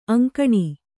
♪ aŋkaṇi